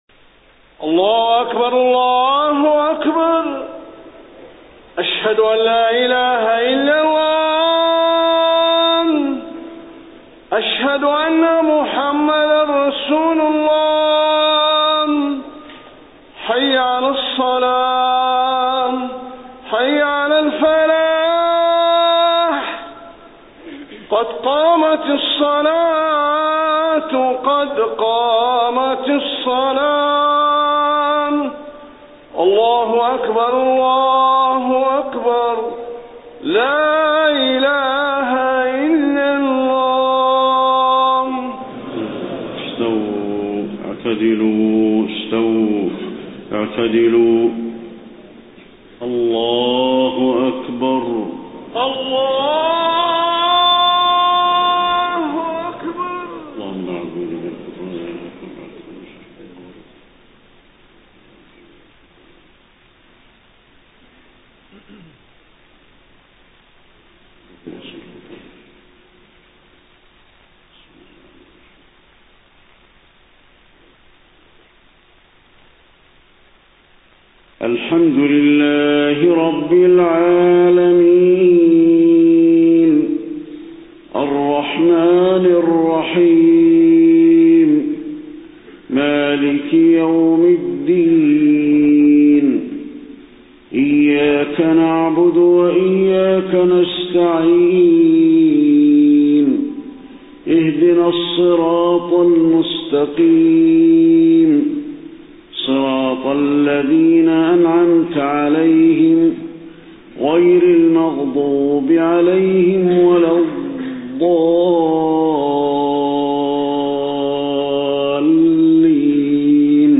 صلاة العشاء 23 صفر 1431هـ سورة المنافقون كاملة > 1431 🕌 > الفروض - تلاوات الحرمين